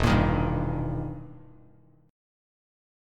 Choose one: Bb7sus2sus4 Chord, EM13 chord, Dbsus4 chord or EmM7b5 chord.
EmM7b5 chord